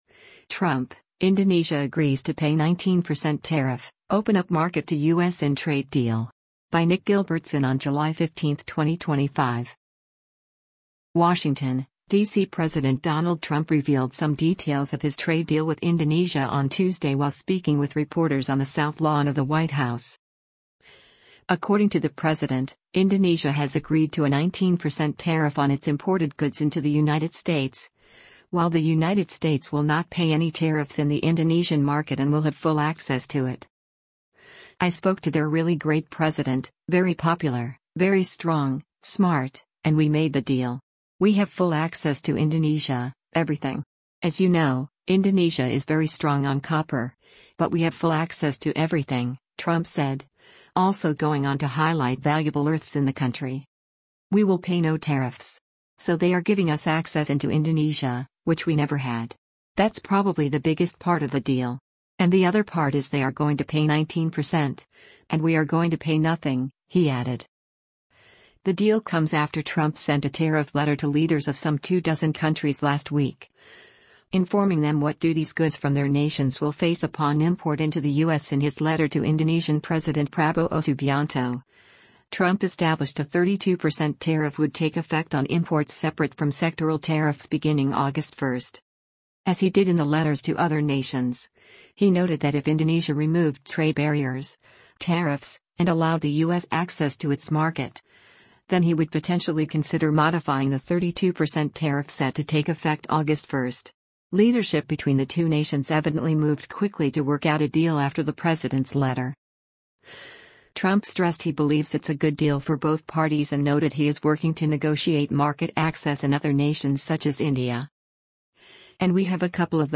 WASHINGTON, DC —President Donald Trump revealed some details of his trade deal with Indonesia on Tuesday while speaking with reporters on the South Lawn of the White House.